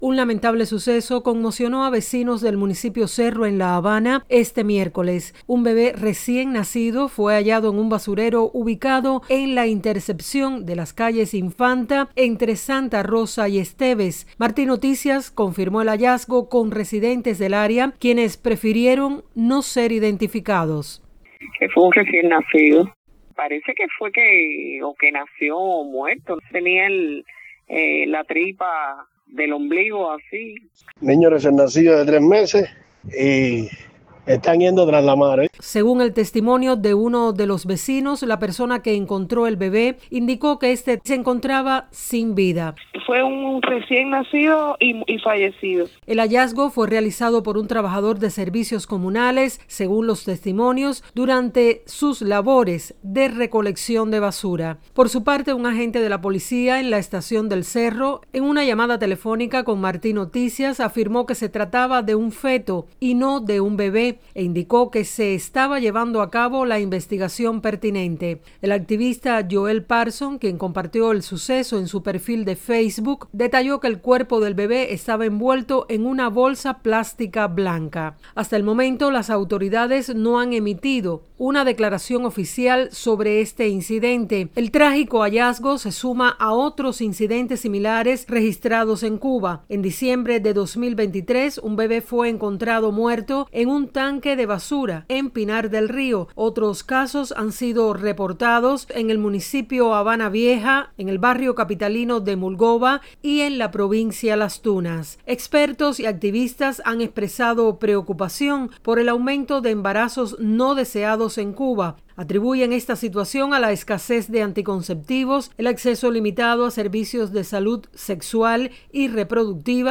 Testimonios: Bebé recién nacido fue hallado en un contenedor de basura en La Habana